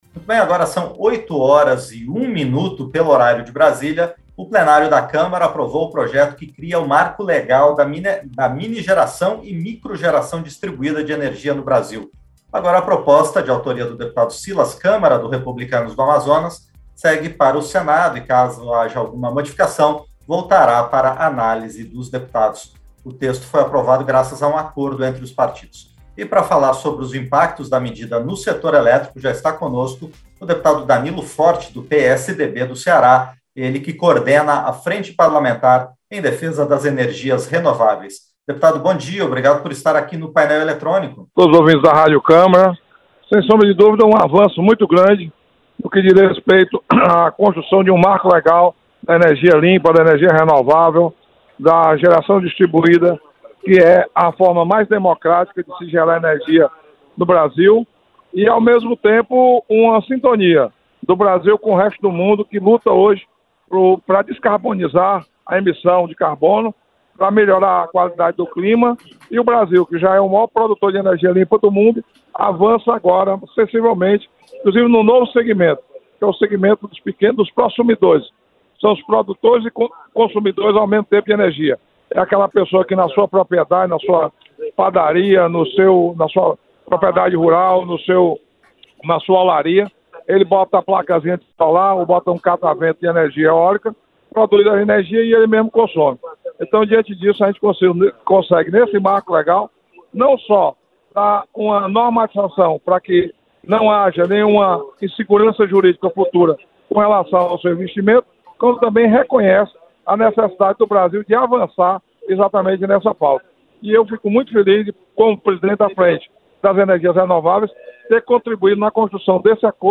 Entrevista - Dep. Danilo Forte (PSDB-CE)